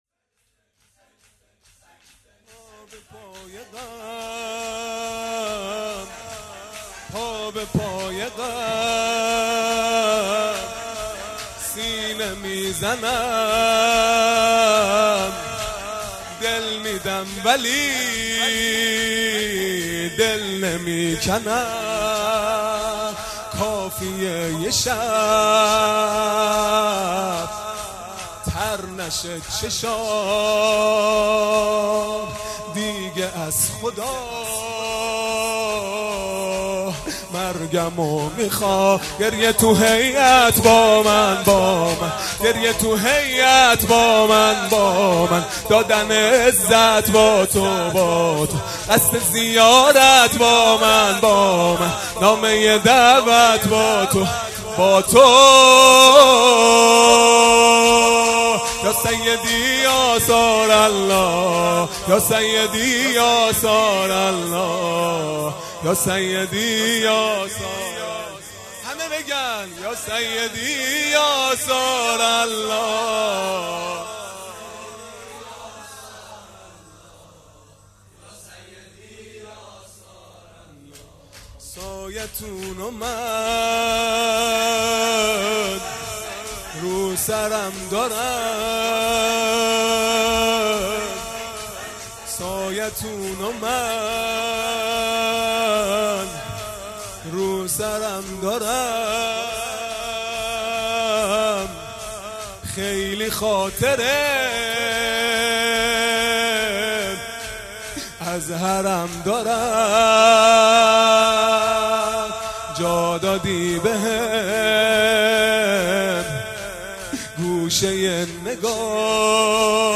0 0 شور
جلسه هفتگی هیات به مناسبت شهادت حضرت حمزه(ع)